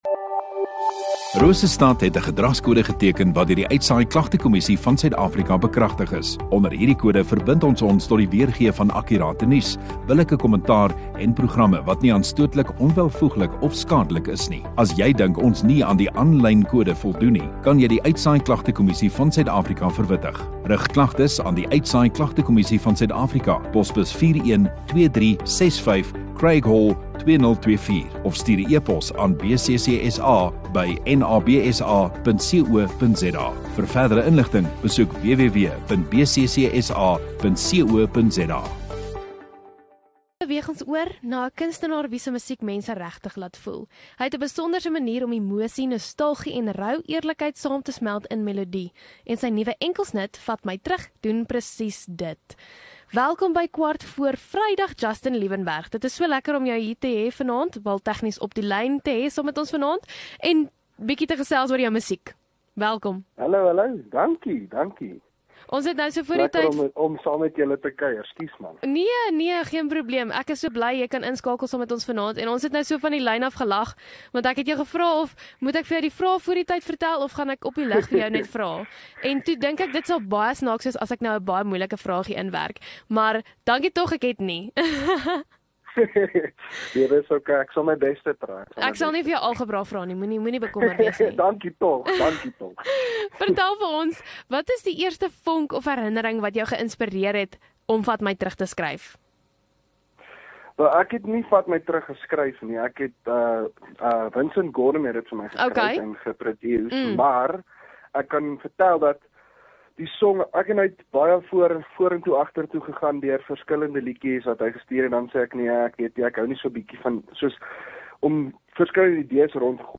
Onderhoude